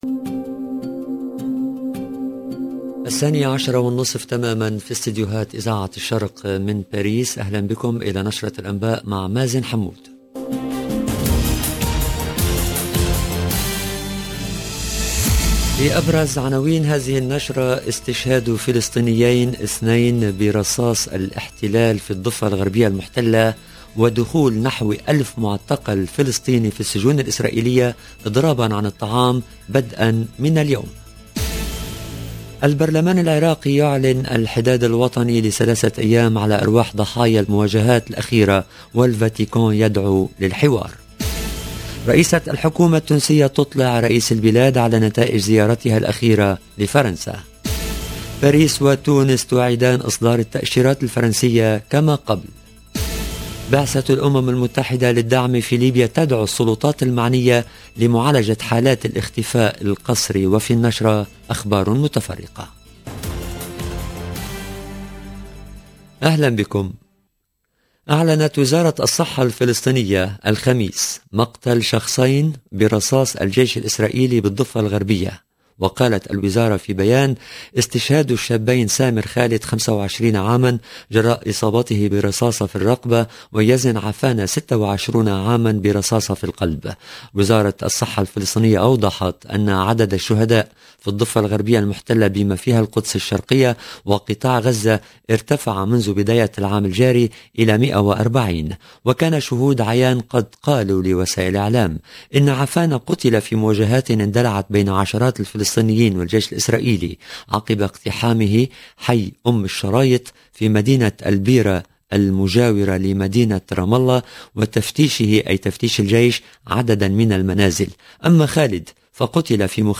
LE JOURNAL DE MIDI 30 EN LANGUE ARABE DU 1/09/22